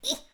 SFX_Battle_Vesna_Attack_12.wav